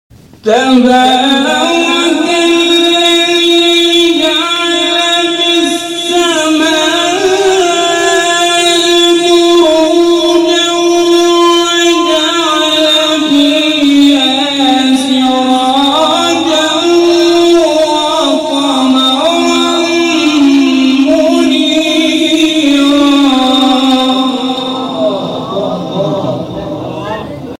گروه شبکه اجتماعی: جدیدترین مقاطع صوتی از تلاوت قاریان بنام و ممتاز کشور را که به تازگی در شبکه‌های اجتماعی منتشر شده است، می‌شنوید.